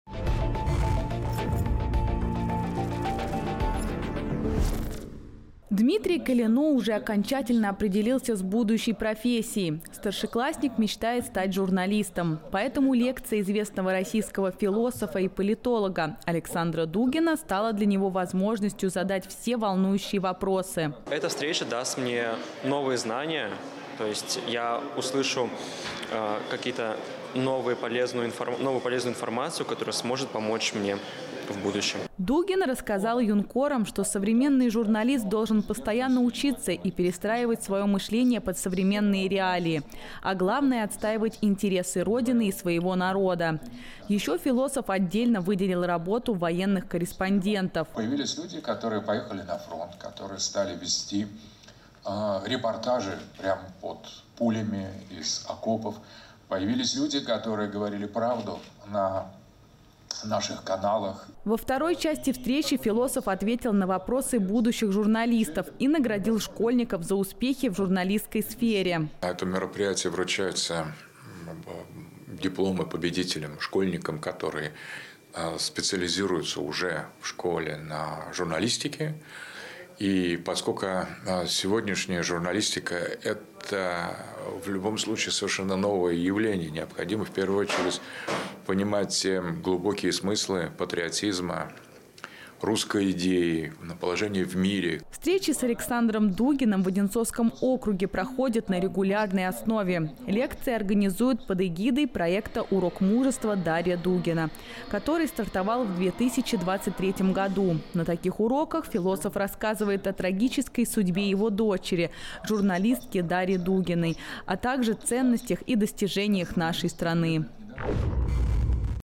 Лекция известного российского философа и политолога Александра Дугина состоялась в Звенигороде. Он поднял тему важности воспитания патриотизма у современных школьников.